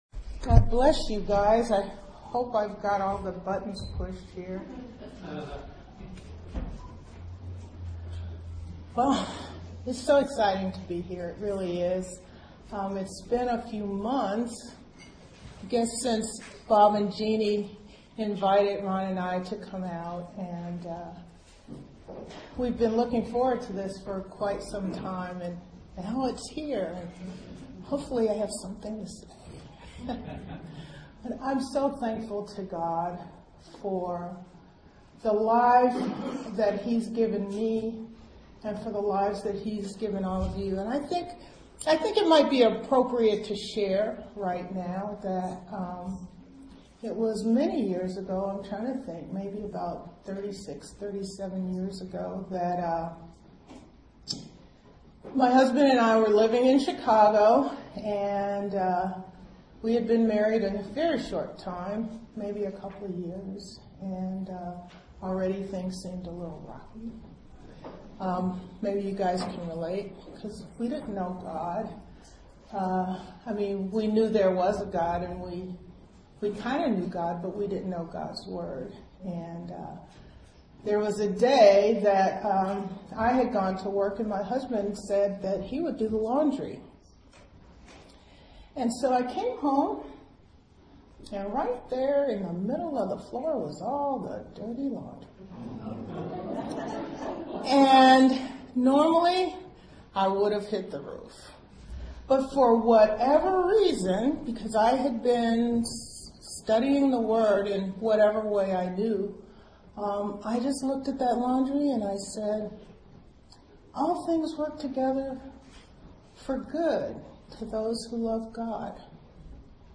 Healing & Deliverance Seminar Part 1